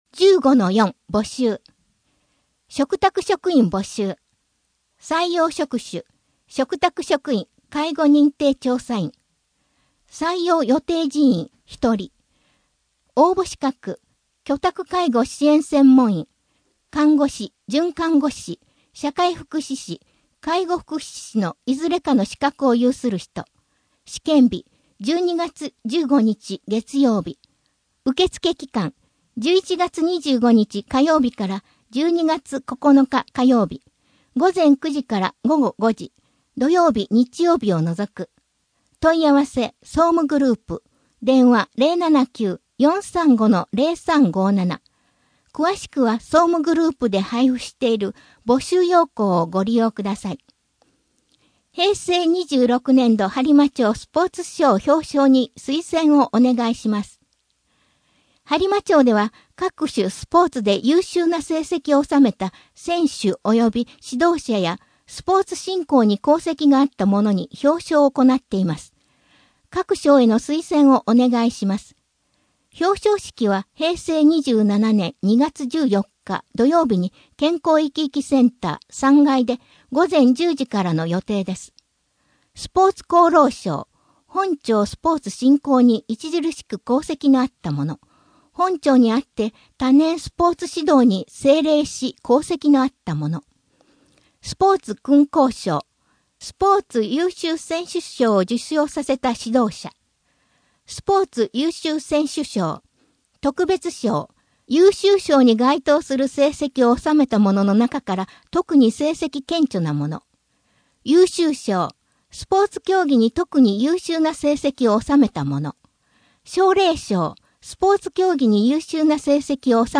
声の「広報はりま」12月号
声の「広報はりま」はボランティアグループ「のぎく」のご協力により作成されています。